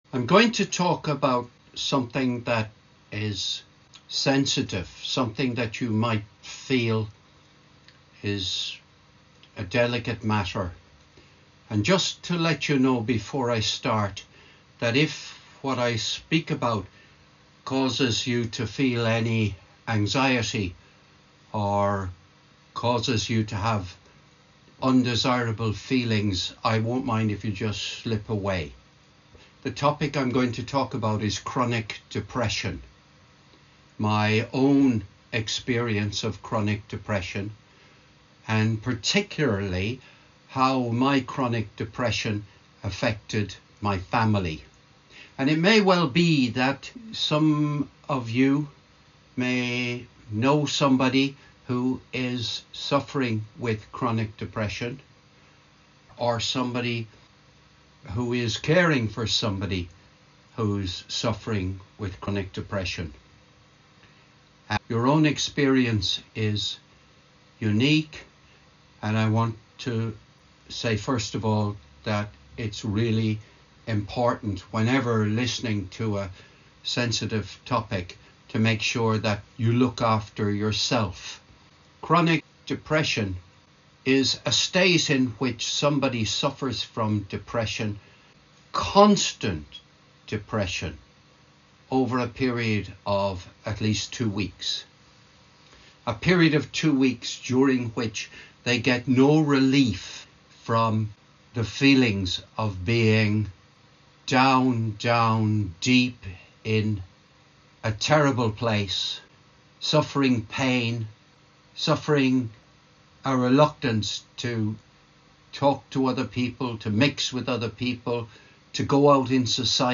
This was recorded on Wednesday 27th November 2024 - at Shilling Speakers Toastmasters Club.